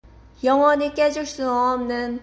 click to listen to the pronunciation)